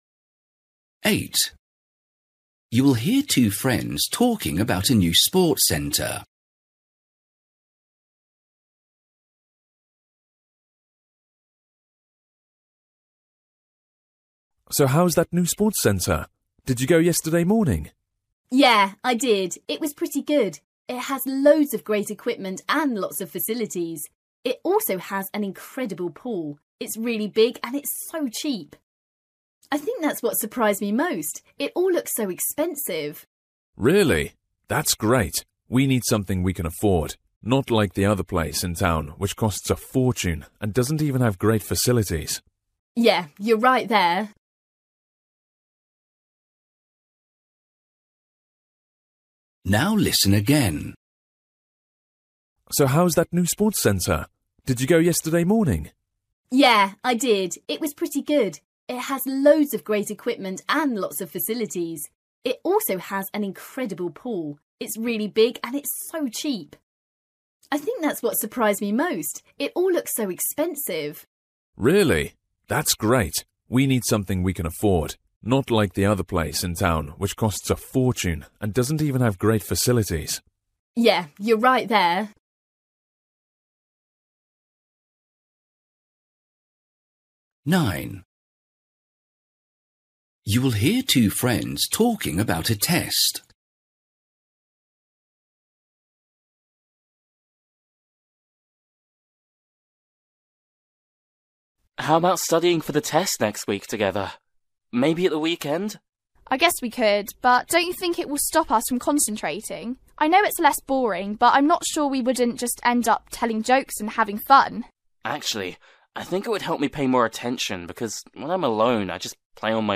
Listening: everyday short conversations
8   You will hear two friends talking about a new sports centre. What amazed the girl most?
9   You will hear two friends talking about a test. The boy thinks studying for a test together with the girl will
12   You hear two friends talking about an article they read. They agree that social media